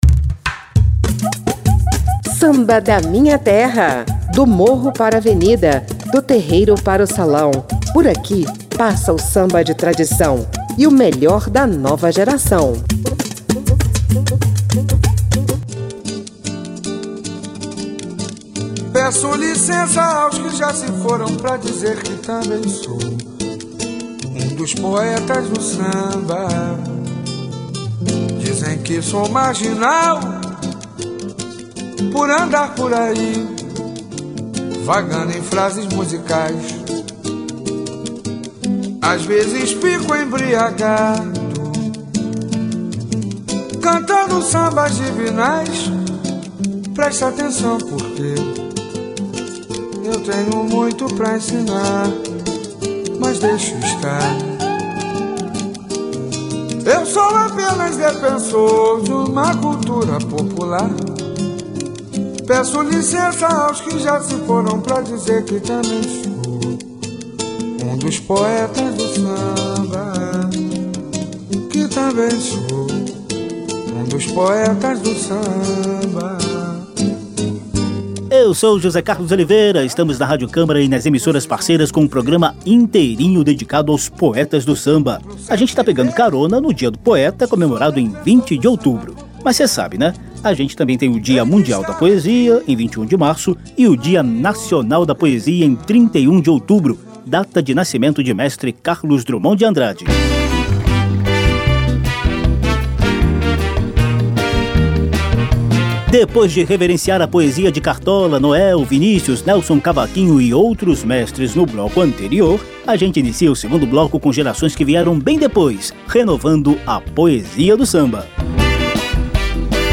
Por essas e outras, Samba da Minha Terra convida os poetas e as poetisas do samba para celebrar os dias de poesia. O quadro “poesia do samba”, que é um espaço permanente no programa, vai se desdobrar em várias sequências musicais com uma seleção de versos e estrofes batucados.